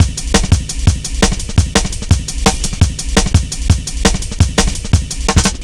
Index of /90_sSampleCDs/Zero-G - Total Drum Bass/Drumloops - 3/track 61 (170bpm)